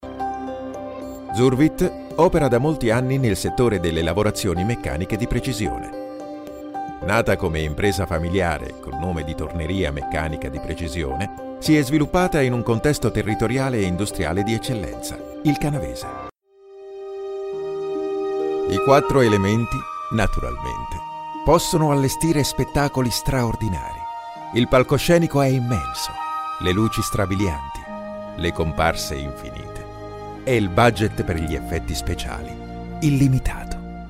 Italian Speaker, Italian Voice over talent, middle/old voice
Sprechprobe: Industrie (Muttersprache):
My voice has a low tone from warm tone and is suitable for commercials institutional advertising and so on, answering machines, documentaries, jingles, audio books, audio guidance, e-learning, voice over, multimedia audio voiceovers